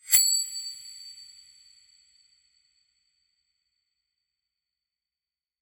OS_RNB_SFX_Medallion_Transition.wav